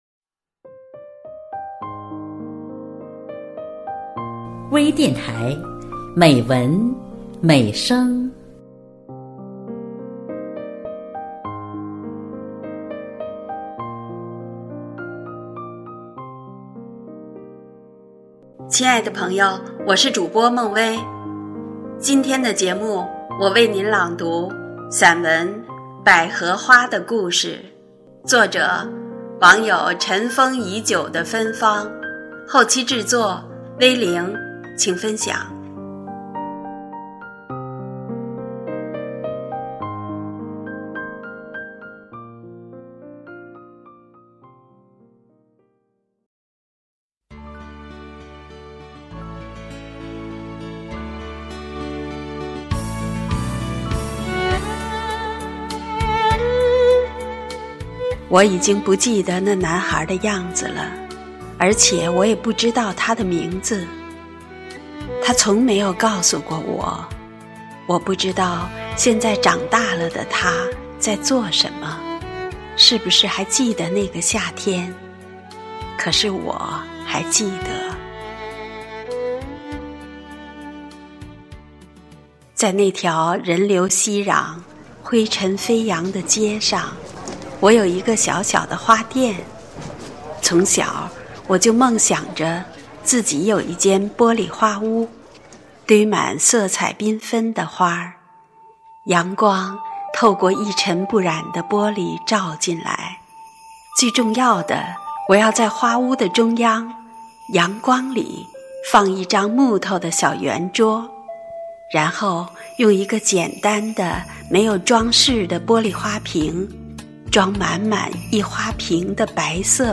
朗诵
多彩美文 专业诵读 精良制作 精彩呈现